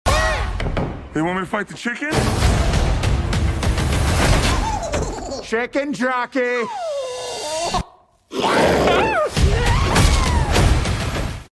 Такое сочетание (серьёзный голос Джека Блэка + смешной визуал) “зашло” фанатам игры и возымело эффект взрыва эмоций.